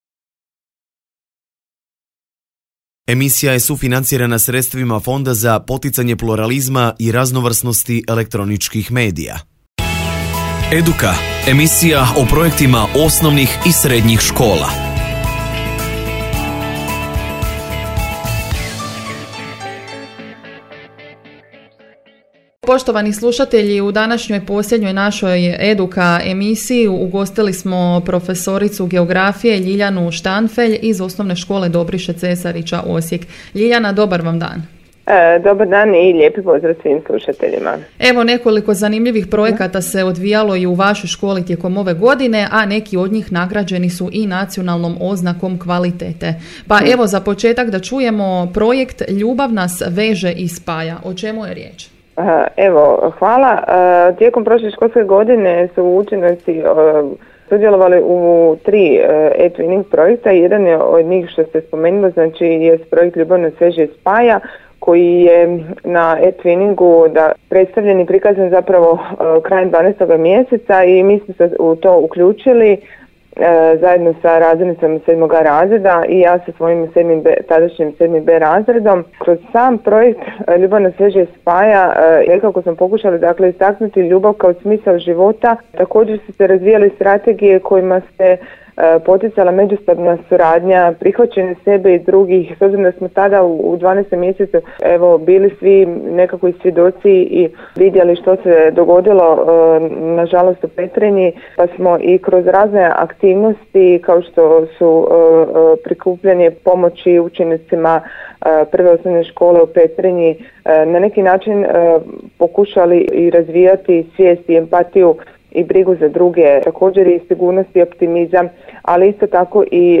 Audio snimku intervjua